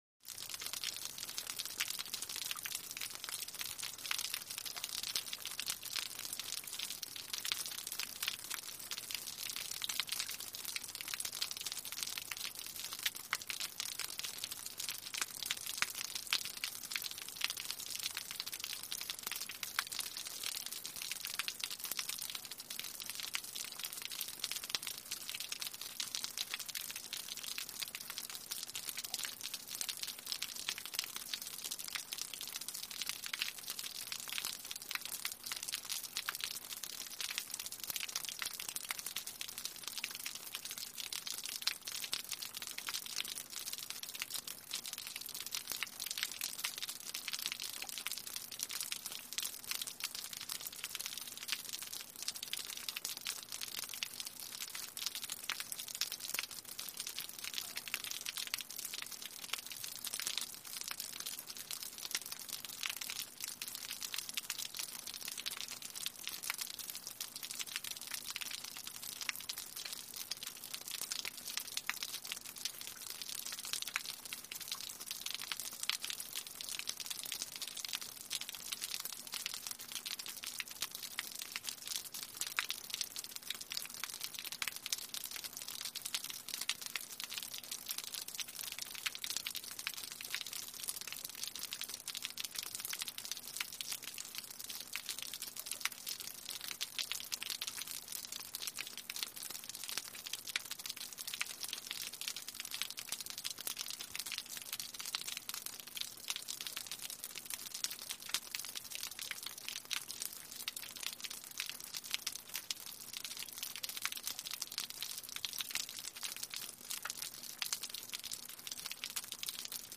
Rain; Medium, Splatty, Fall On Asphalt From Roof Of House.